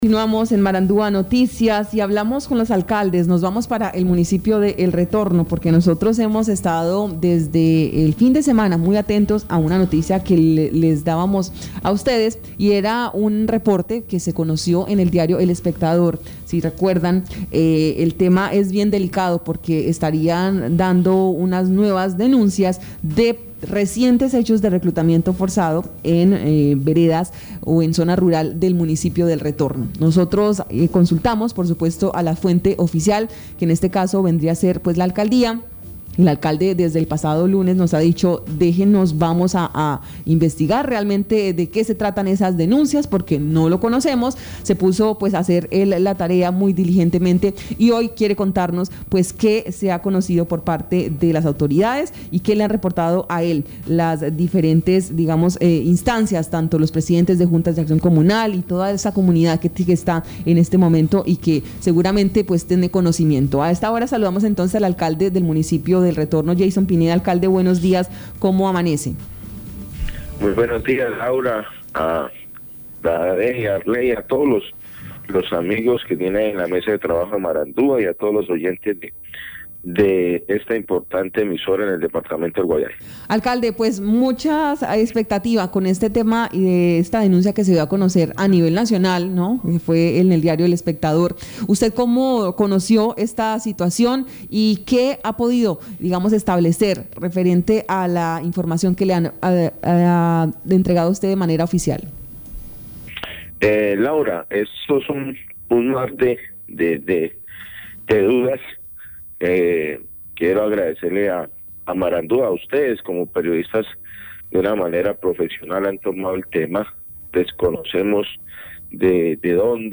Escuche a Yeison Pineda, alcalde de El Retorno, Guaviare.